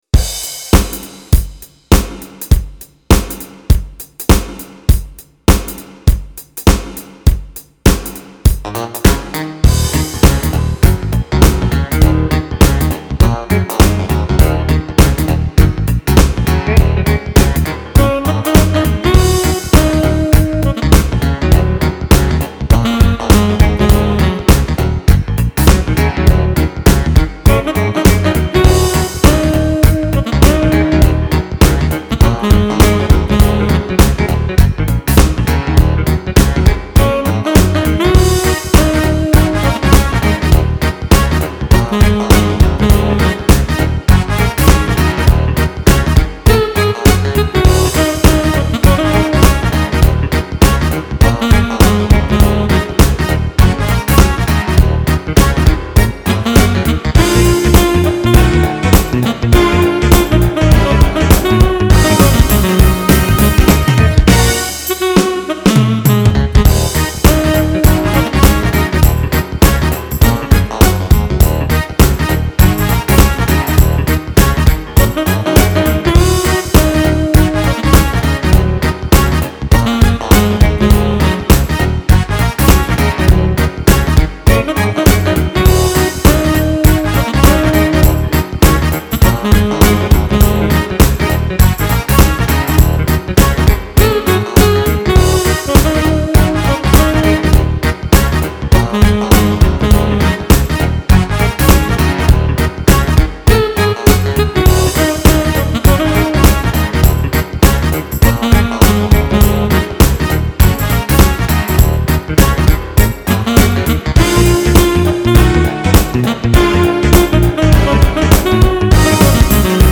As soon as that clavinet kicks in after the drum intro